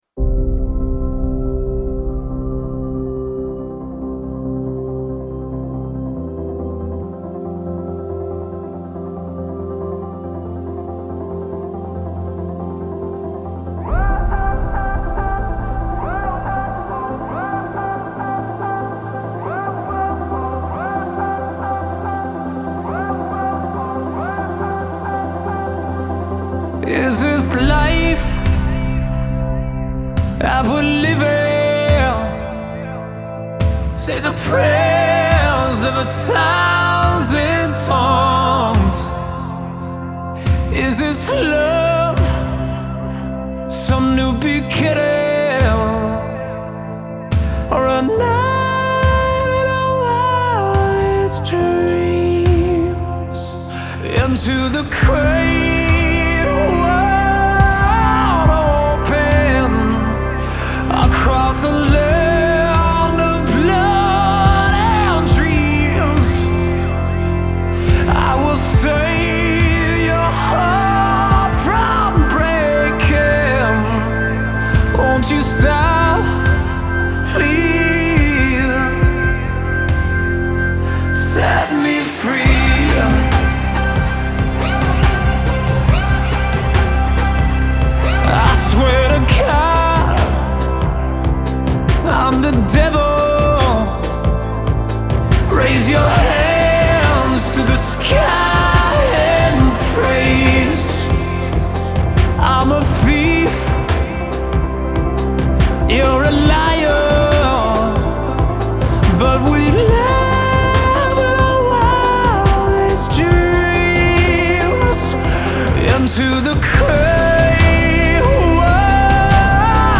мощная рок-композиция